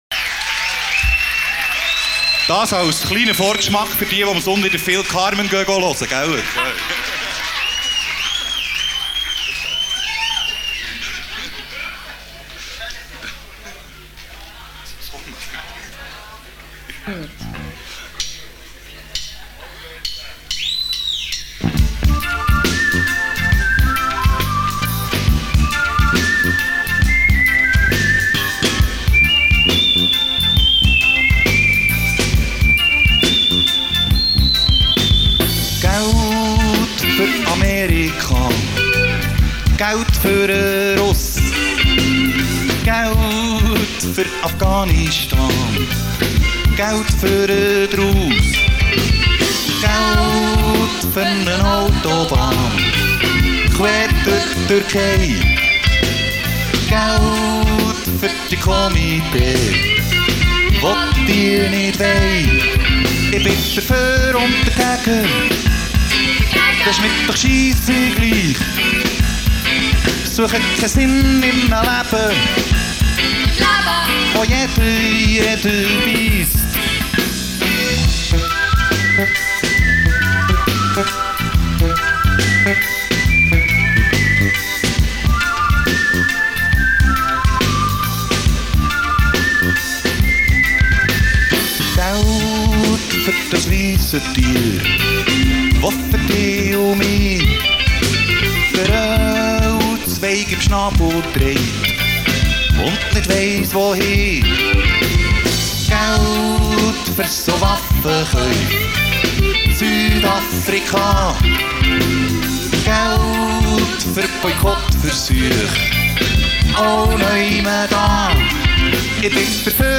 gesang
gitarre
bass
synts
schlagzeug